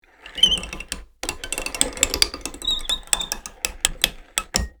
Download Free Gears Sound Effects | Gfx Sounds
Gear-mechanism-rotating-clicky-4.mp3